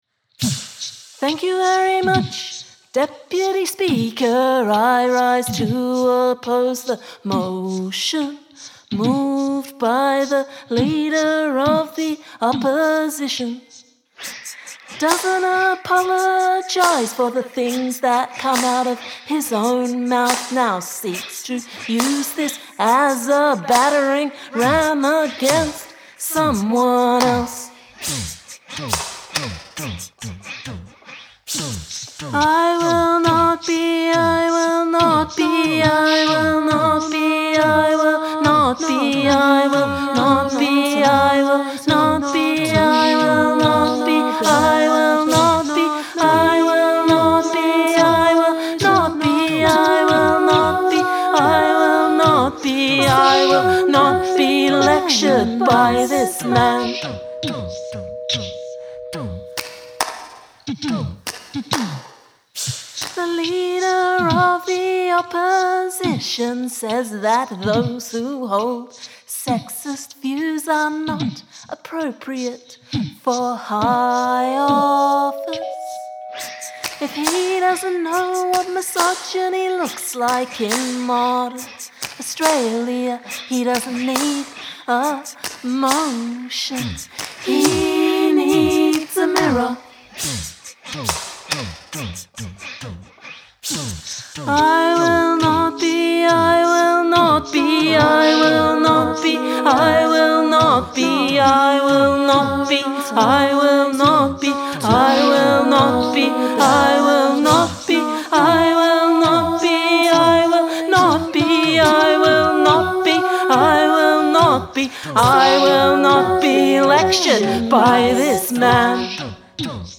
Include an a cappella section
Did you sample your own voice for those pad sounds?